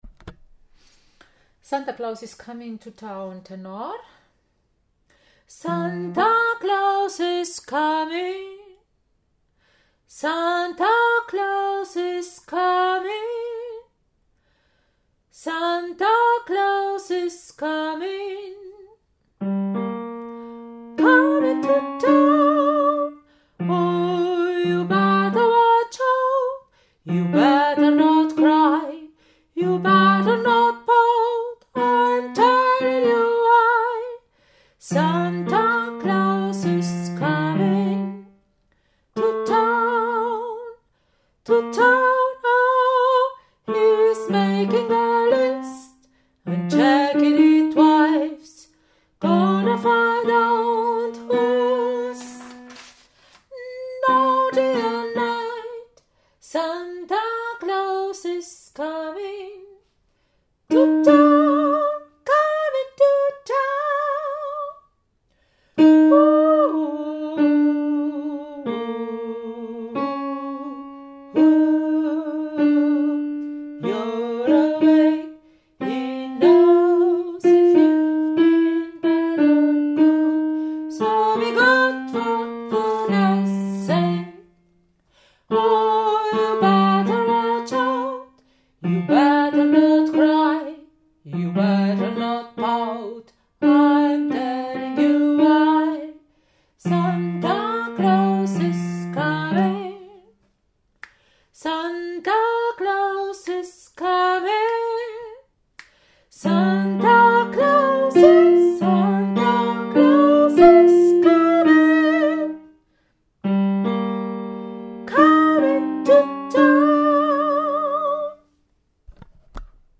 Santa-Claus-is-comin-Tenor.mp3